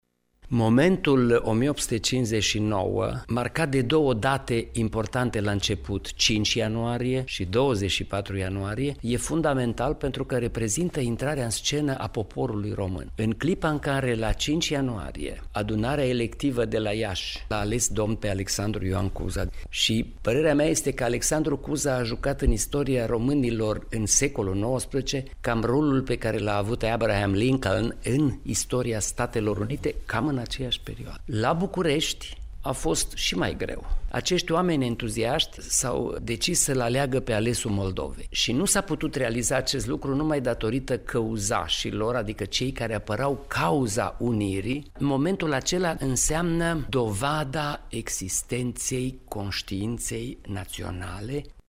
Este de fapt momentul formării României, la Iaşi şi la Bucureşti, o adevărată dovadă a existenţei conştiinţei naţionale, explică istoricul Ioan Aurel Pop, rectorul Universitatii Babes-Bolyai: